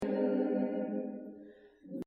sur certaines fréquences on entend clairement une sorte de chorus ou flanging ! et les voix sont quand-même un peu dénaturées dans l'ensemble - en tout cas le fichier "choeur" (les voix toutes seule) contient pas mal d'artefacts et mauvaises résonances tout le long; et on l'entend très fortement sur les fins de phrase et à la respiration des chanteurs/euses (résonance genre boite de conserve)
et puis tu verras qu'une fois que tu as "repéré" les "colorations flanging" on les entend clairement tout le long de l'enregistrement... ces colorations sont dues aux déphasages
c'est sans doute dû à l'utilisation et emplacement de nombreux micros et leur différences de qualité (mais aussi dû en partie à la reverbe du lieu)